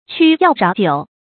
詘要橈腘 注音： ㄑㄩ ㄧㄠˋ ㄖㄠˊ ㄍㄨㄛˊ 讀音讀法： 意思解釋： 彎腰曲膝。